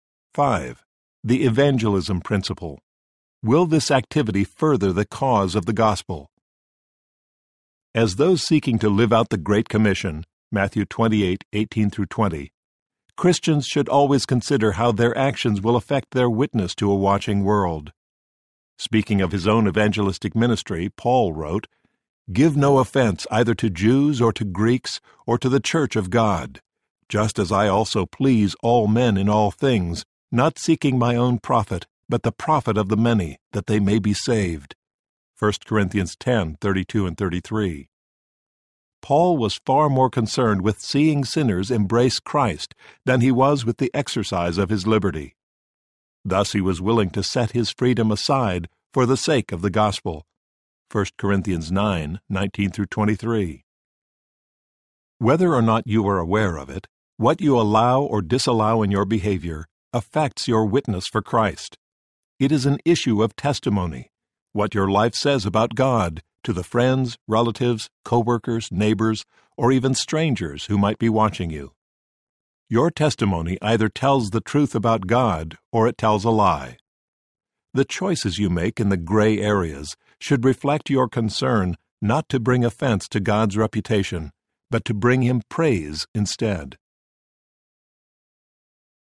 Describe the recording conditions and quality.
7.68 Hrs. – Unabridged